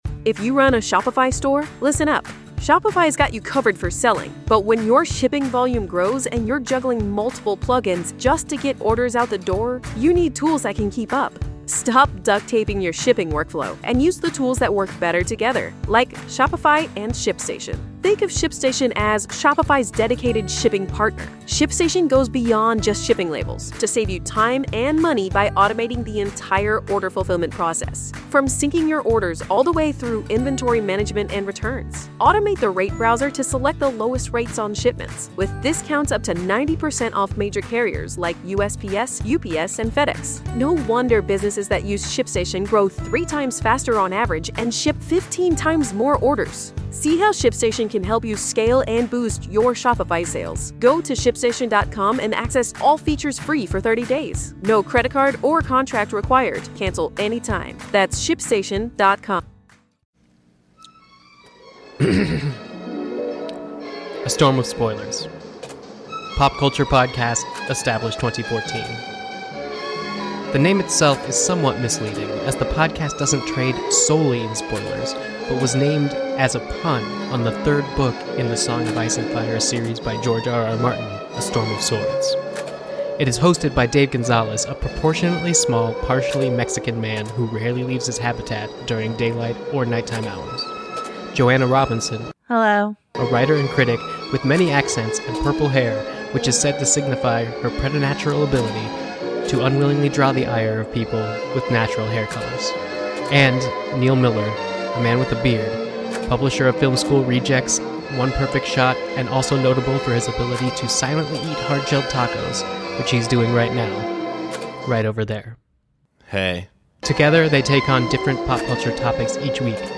This week - All three hosts dive into the works of Wes Anderson!